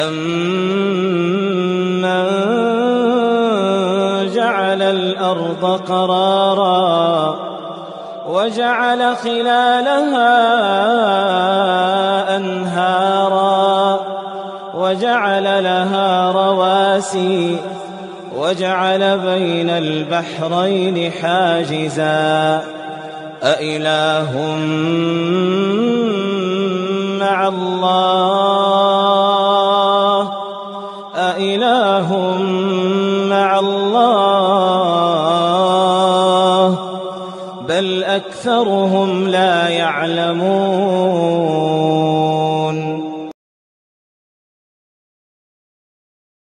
تلاوة خاشعة مميزة